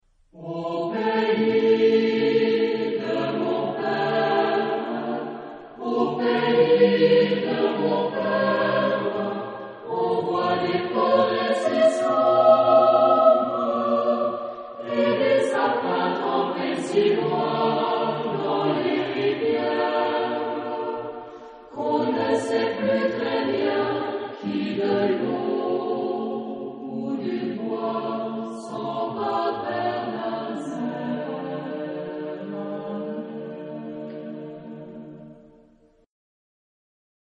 Genre-Style-Form: Secular ; Popular
Mood of the piece: taut ; humorous
Type of Choir: SATB  (4 mixed voices )
Tonality: D minor